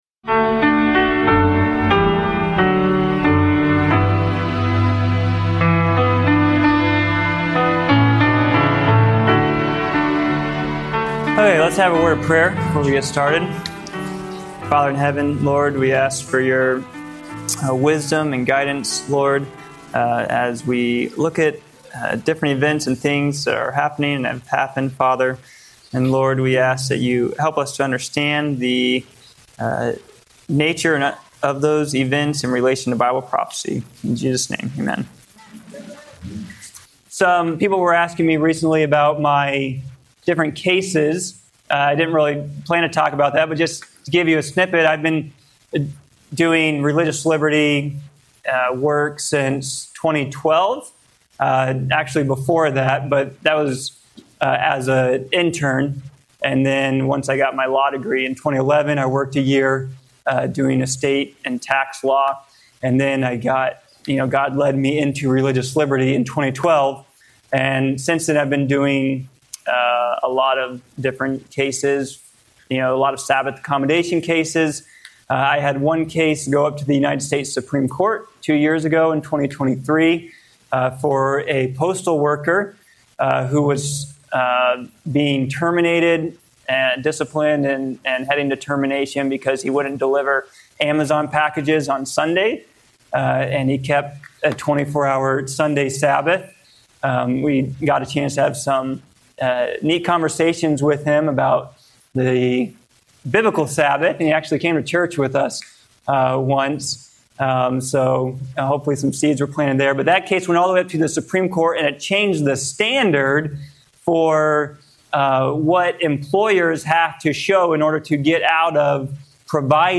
This eye-opening lecture explores the growing tension between religious liberty and government influence, highlighting legal precedents, historical warnings, and the potential erosion of constitutional safeguards. With a focus on judicial decisions, church-state separation, and the rising threat to Sabbath observance, it challenges viewers to defend the principles of true religious freedom.